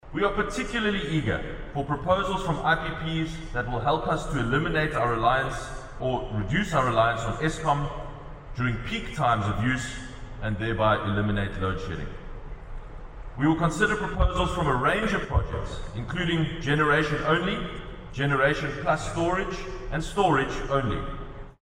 The mayor was addressing delegates at the Solar Power Africa conference at the CTICC.